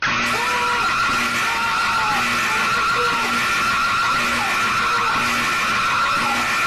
Loud Alarm!!! Botão de Som